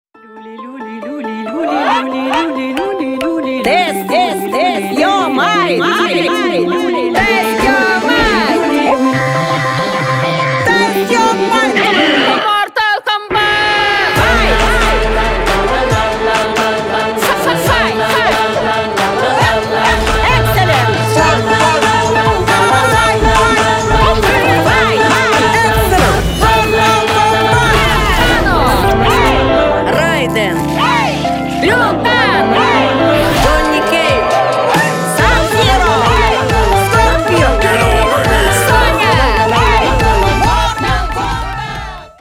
• Качество: 320, Stereo
веселые
смешные